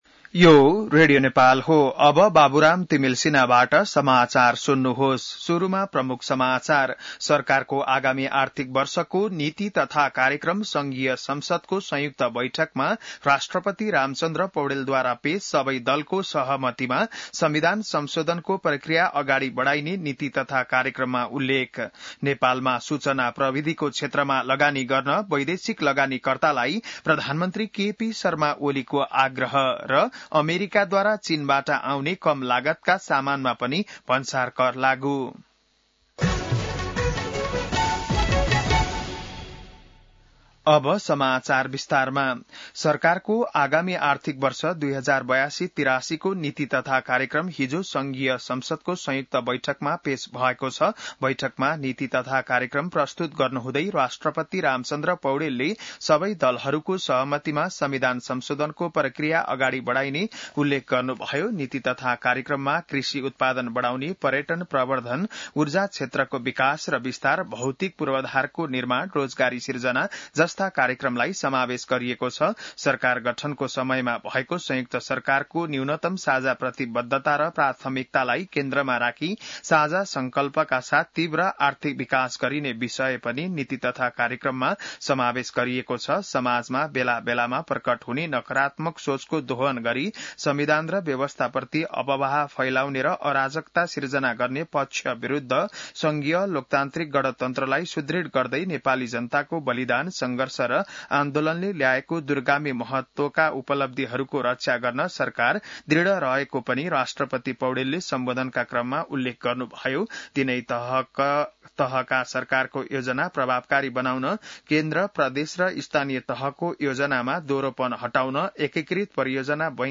बिहान ९ बजेको नेपाली समाचार : २० वैशाख , २०८२